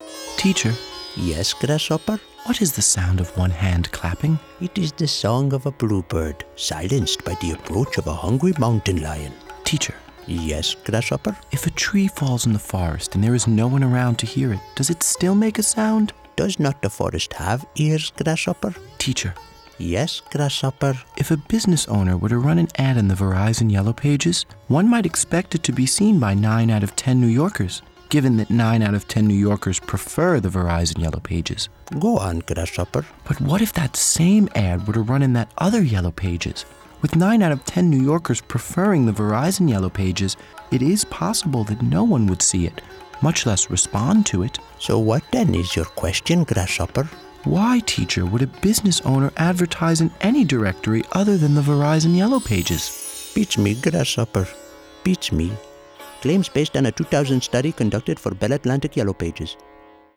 VOICE OVERS Television and Radio
RADIO